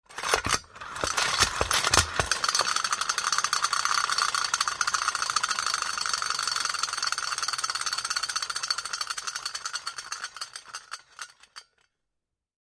Звуки юлы
На этой странице собраны звуки юлы — от легкого жужжания до быстрого вращения.